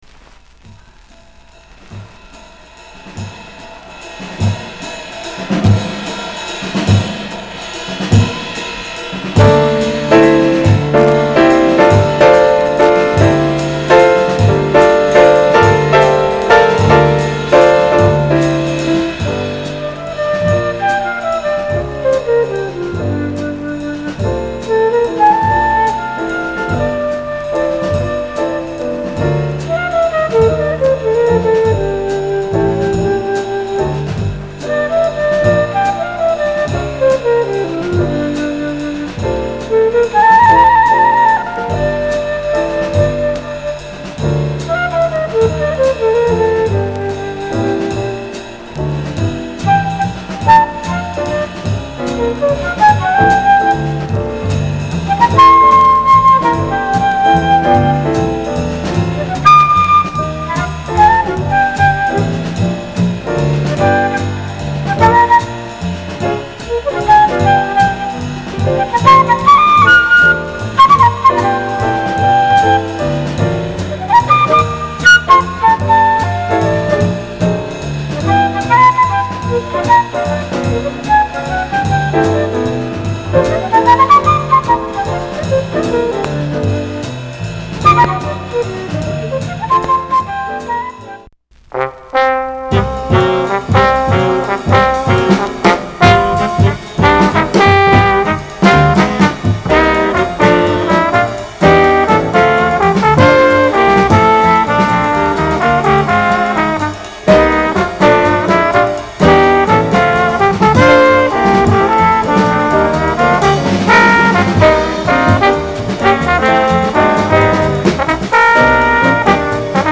discription:Mono灰両溝
この時期らしく新主流派寄りのアプローチが目を引く一枚。